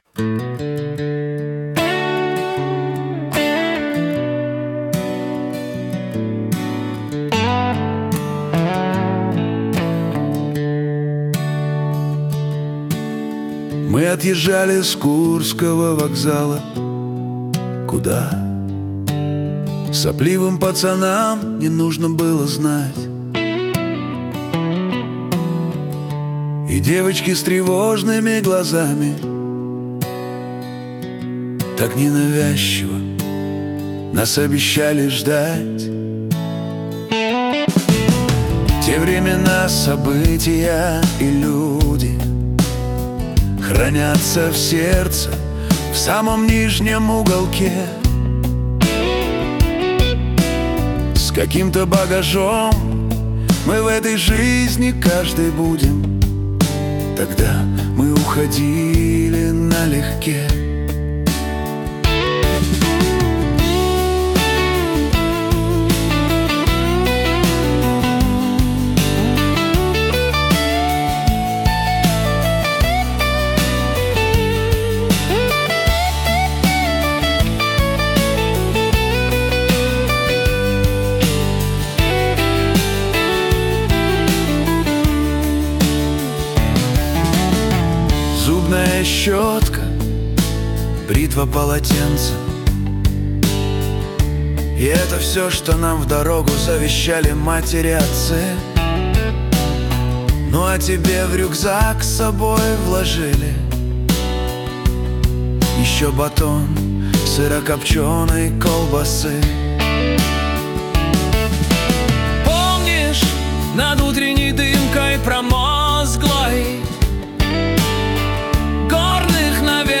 • Жанр: AI Generated